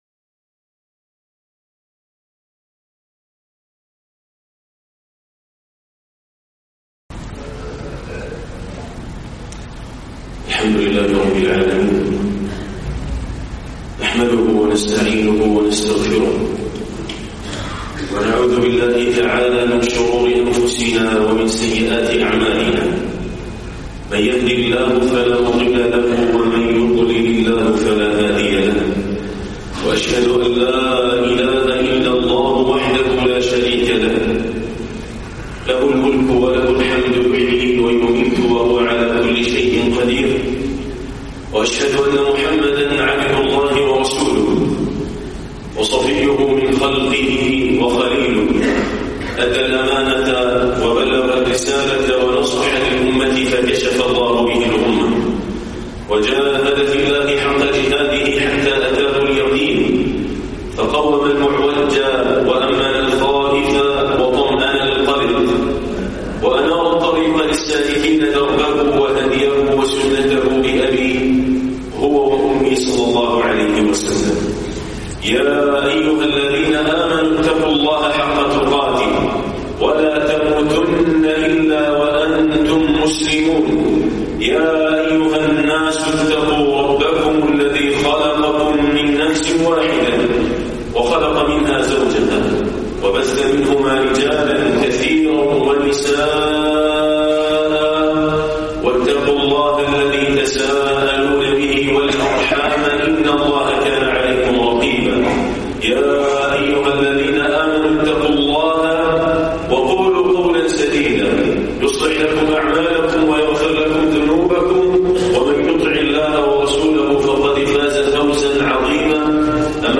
حقوق الوالدين - خطبة